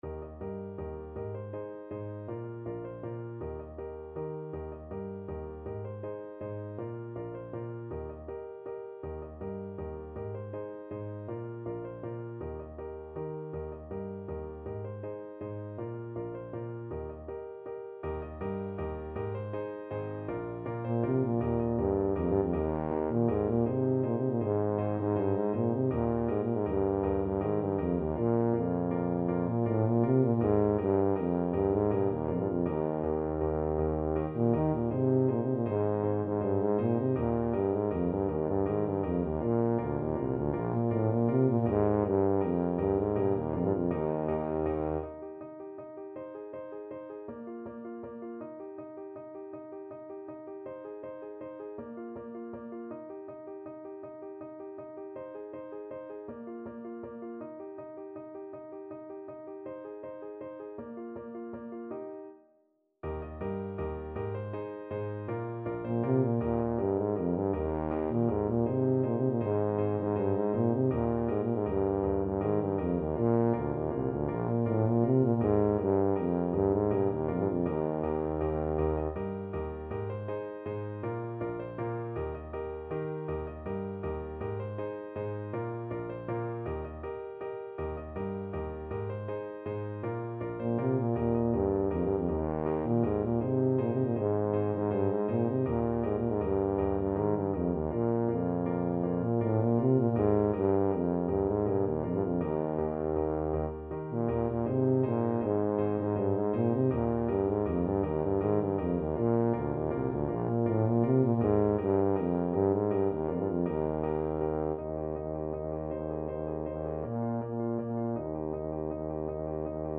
Classical Luigi Boccherini Procession of the Military Night Watch in Madrid (Theme from Master and Commander ) Tuba version
Tuba
3/4 (View more 3/4 Music)
Eb major (Sounding Pitch) (View more Eb major Music for Tuba )
Allegro Vivo = 160 (View more music marked Allegro)
Classical (View more Classical Tuba Music)